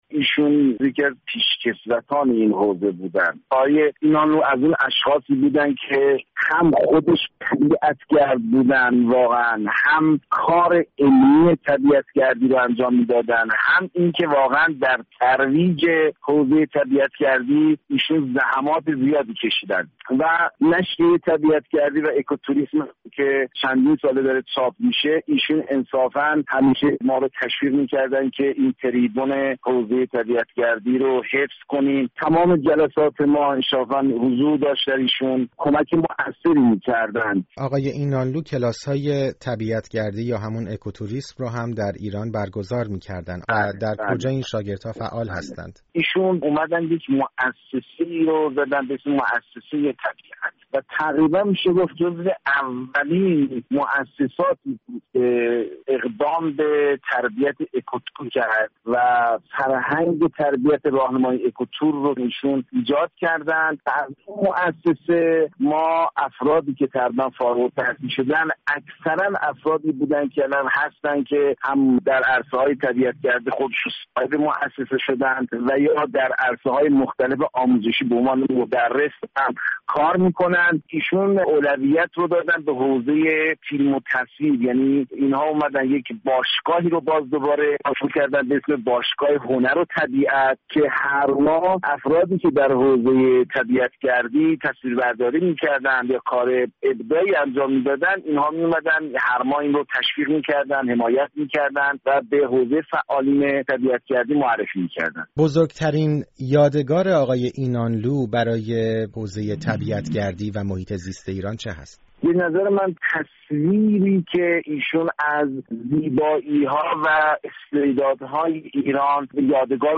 به همین مناسبت گفت‌وگویی داشته‌ایم